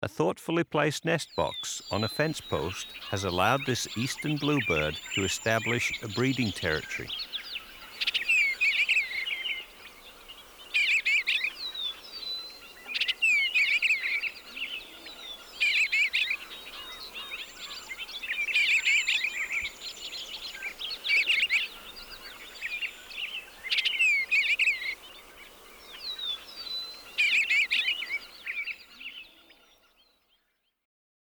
Eastern Bluebird’s Song
78-eastern-bluebird.m4a